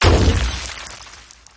AsteroidHit.mp3